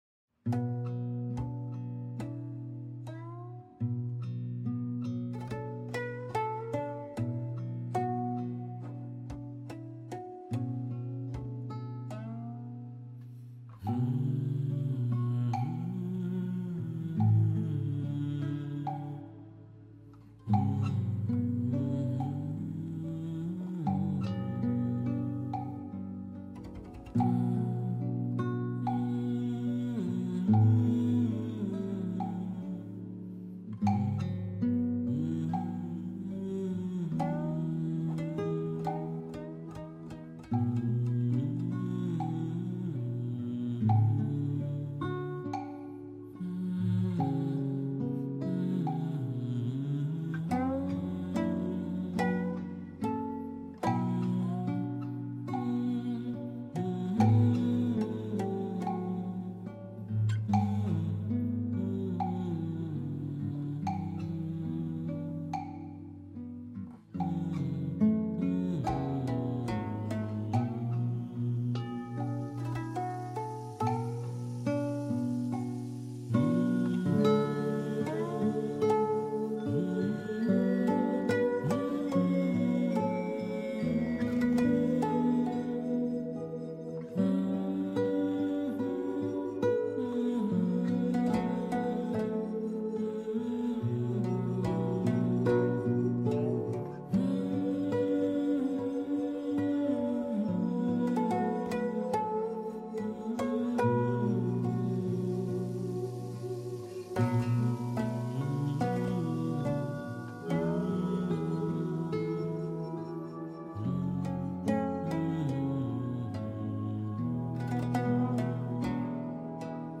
The Zen master returns to Lingshan \ Pentatonic Meditation Music \ 432Hz